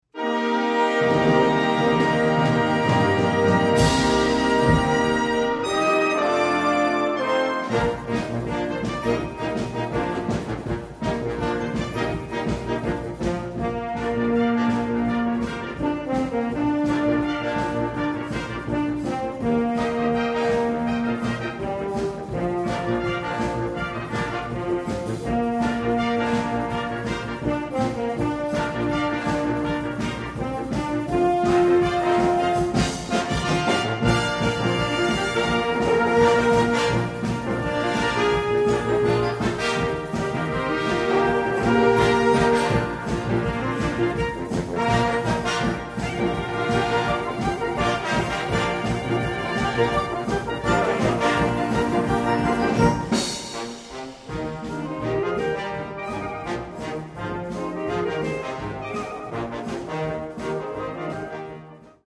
Een aantal traditionele Britse liederen
in een ritmisch modern jasje gestoken.